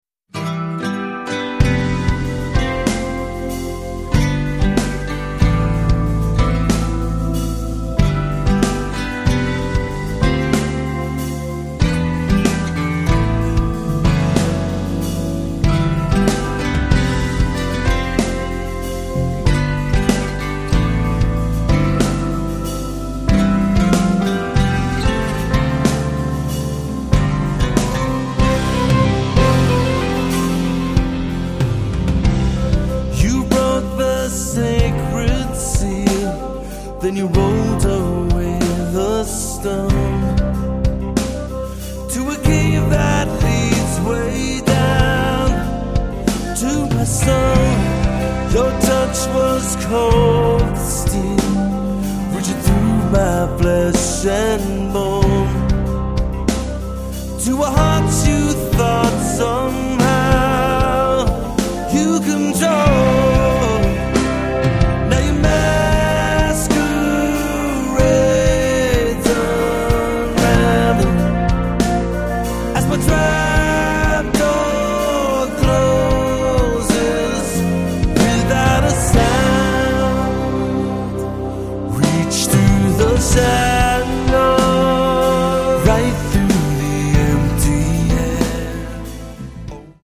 Le son est actuel. Les orchestrations sont bien léchées.